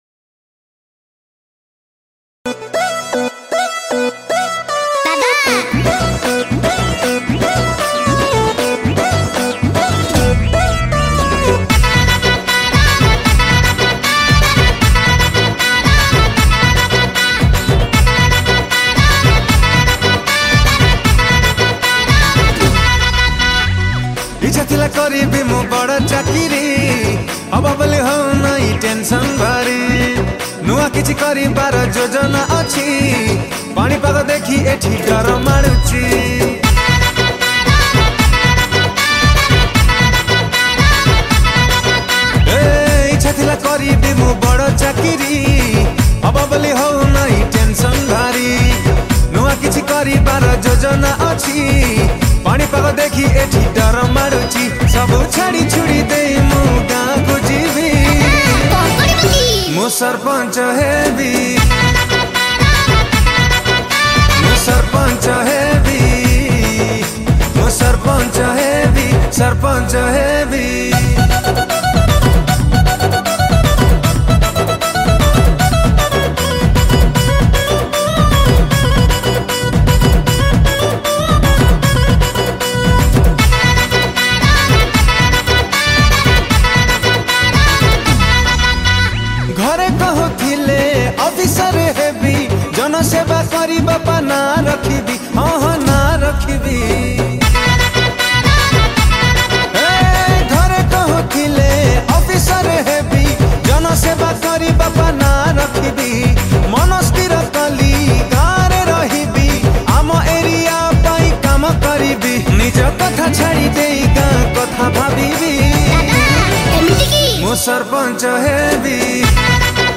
Keyboard Programing
Drums